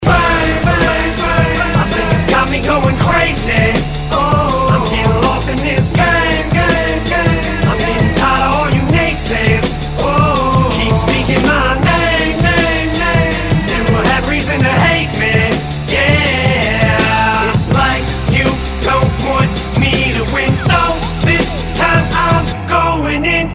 Главная » Файлы » Hip-Hop, RnB, Rap